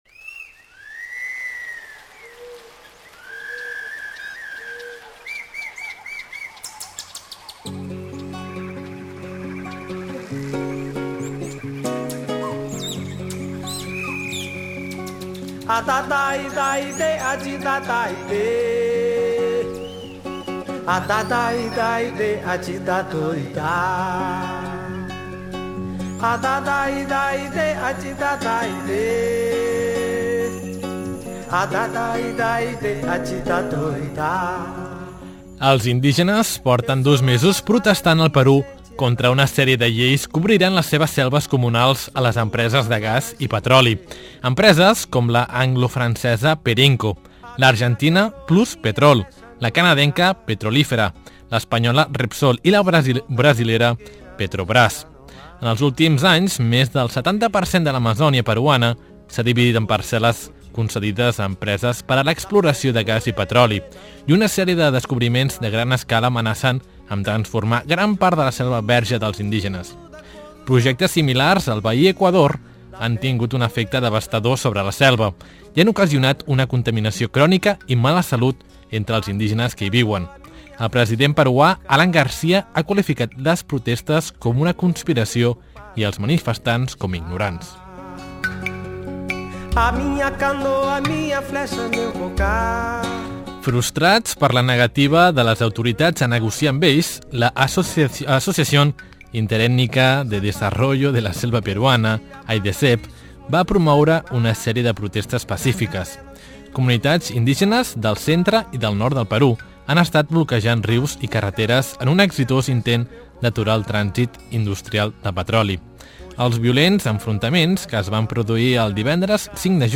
Escucha el REPORTAJE sobre los pueblos indígenas del Perú